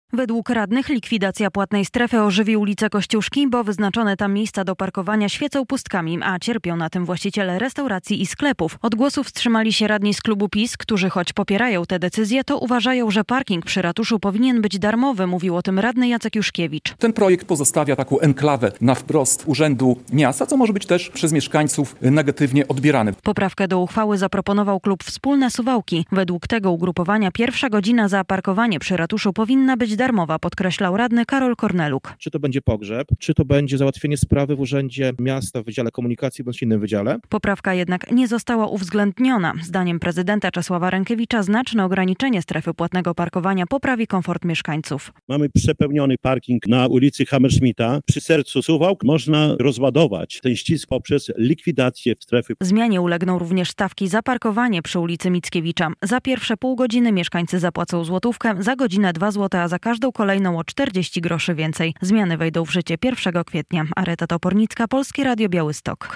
Decyzja radnych w sprawie strefy płatnego parkowania - relacja